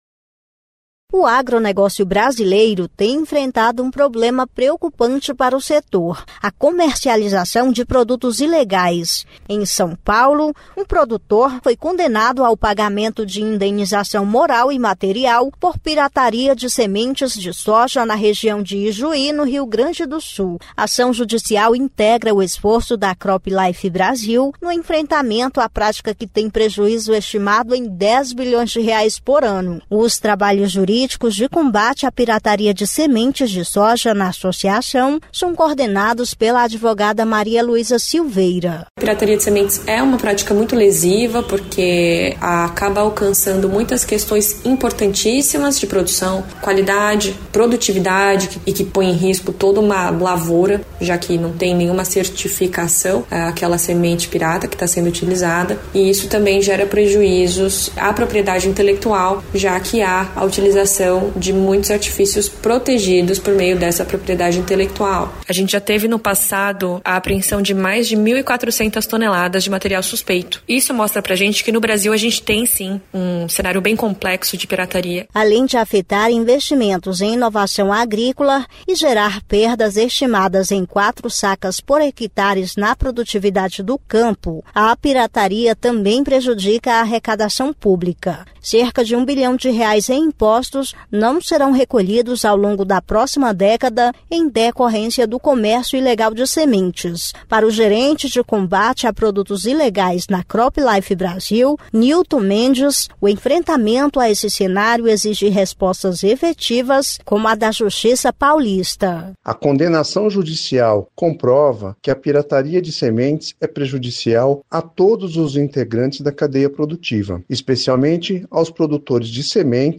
[Rádio] Justiça condena produtor por pirataria de sementes de soja - CropLife